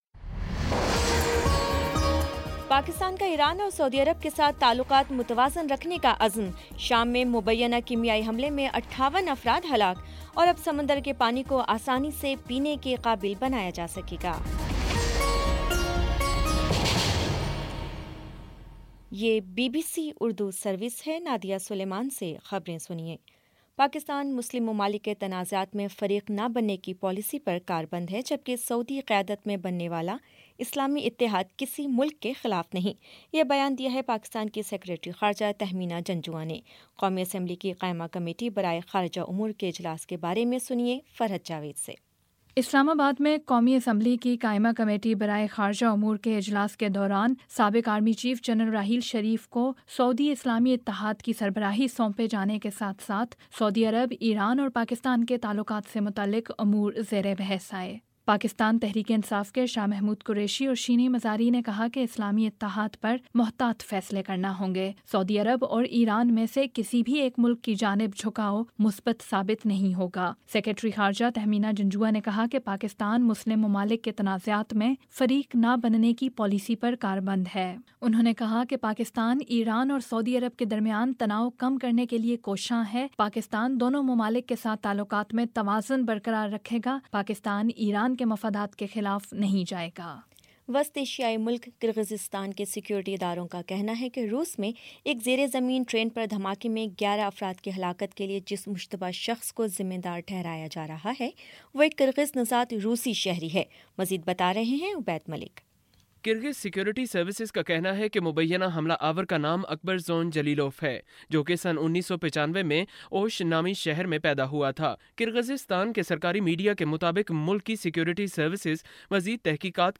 اپریل 04 : شام سات بجے کا نیوز بُلیٹن